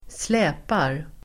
Uttal: [²sl'ä:par]